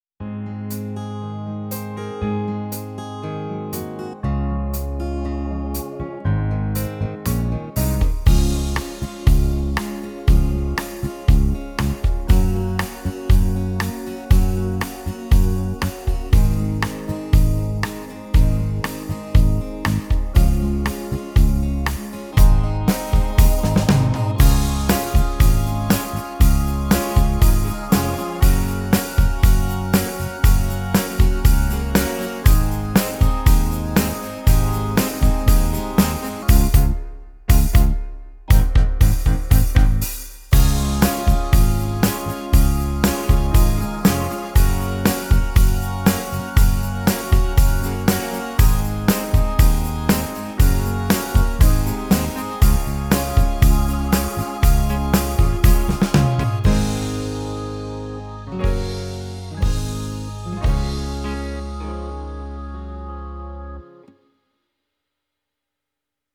Yamaha SFF2-format: Style File Format.
DEMO